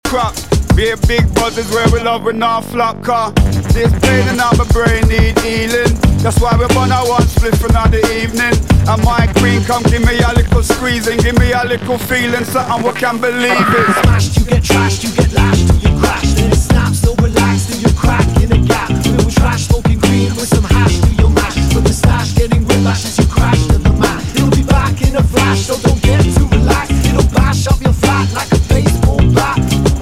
TOP >Vinyl >Drum & Bass / Jungle
Funky Mix